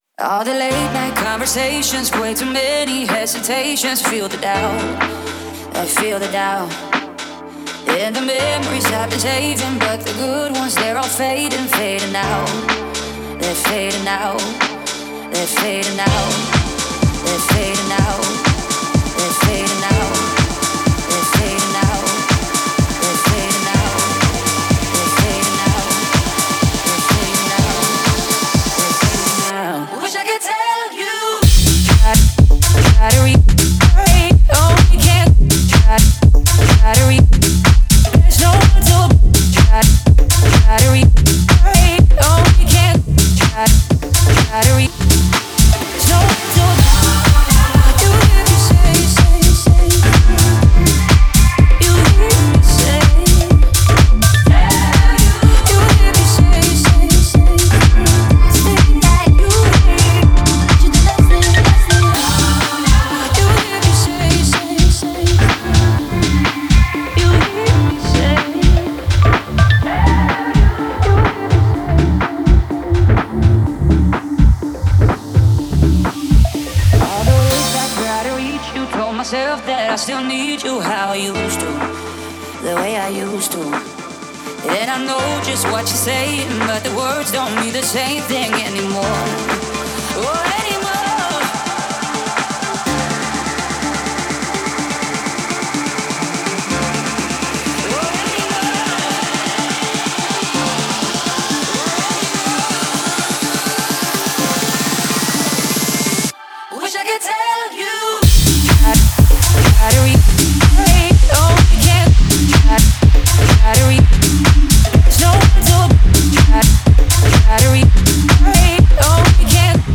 это энергичный трек в жанре хаус